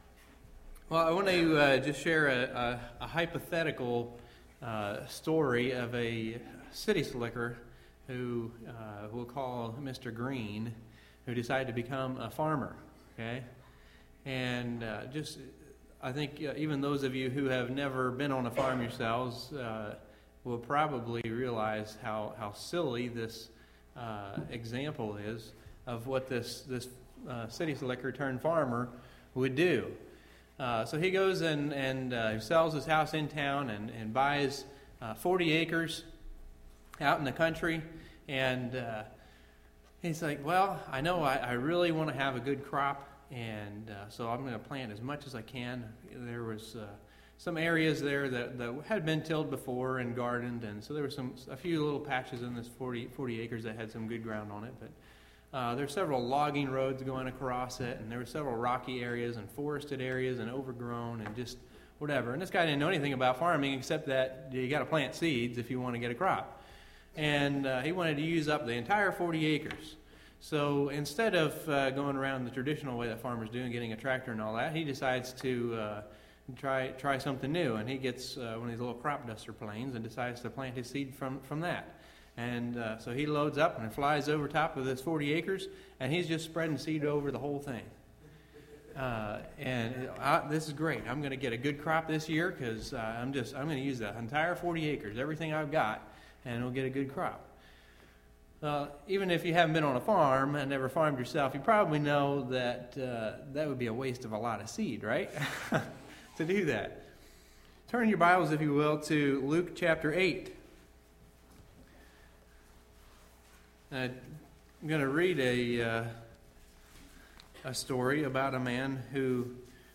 Sunday, April 24, 2011 – Evening Message